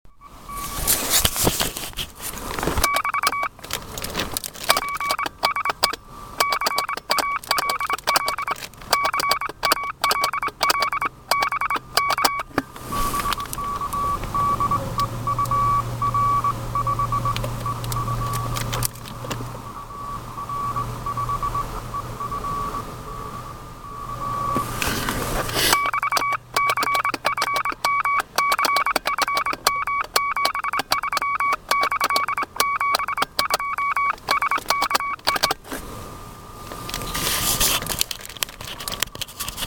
Работал сегодня в лесах-полях QRP/p. Кому интересно
аудио- запись приёма одно- ваттного сигнала